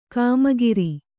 ♪ kāmagiri